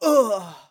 xys死亡5.wav 0:00.00 0:00.72 xys死亡5.wav WAV · 62 KB · 單聲道 (1ch) 下载文件 本站所有音效均采用 CC0 授权 ，可免费用于商业与个人项目，无需署名。
人声采集素材